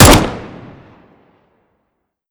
Index of /server/sound/weapons/dod_m1911
usp_unsilenced_02.wav